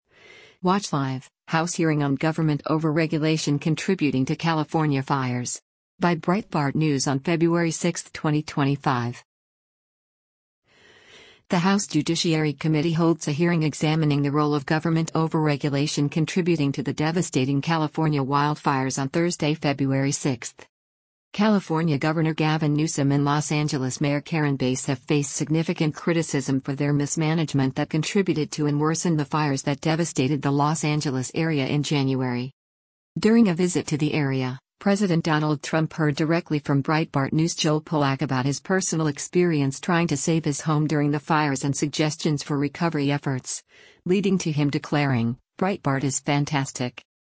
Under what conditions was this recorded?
The House Judiciary Committee holds a hearing examining the role of government overregulation contributing to the devastating California wildfires on Thursday, February 6.